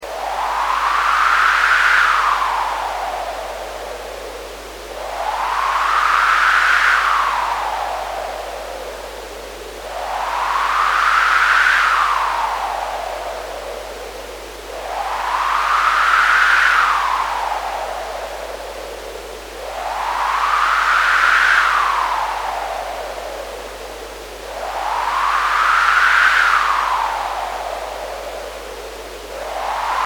This article gives seven examples of sounds you can produce with Sound Lab, the simple single-board analogue synthesiser that I described in the earlier article Sound Lab - a Simple Analogue Synthesiser:
Howling wind
This uses the Noise module, filtered by the Filter module modulated by the Envelope Generator module.
wind.mp3